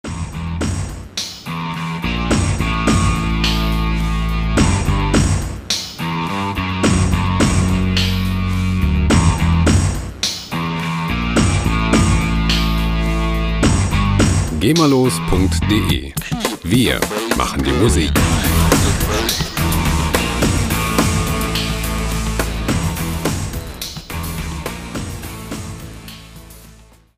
Heavy Metal Loops
Musikstil: Industrial Rock
Tempo: 107 bpm